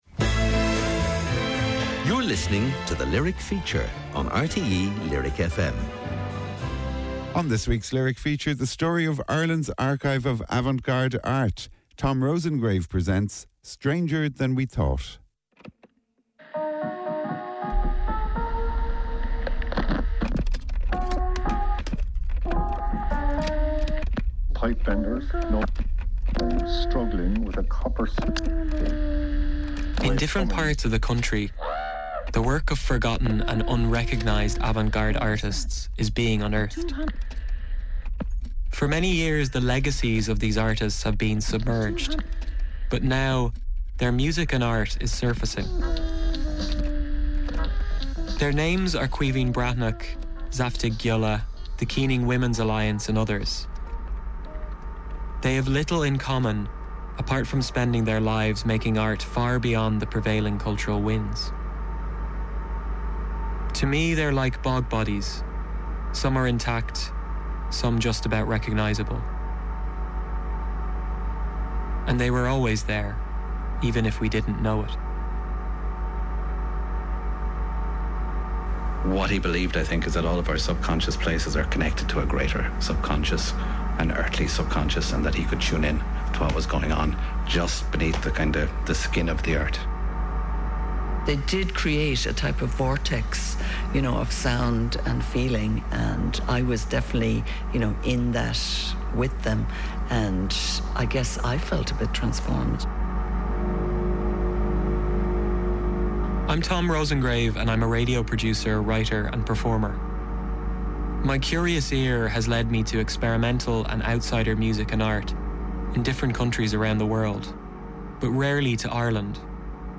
Irish broadcaster RTÉ lyric fm's weekly documentary slot. Programmes about music, literature, visual arts and other areas where creativity is manifest.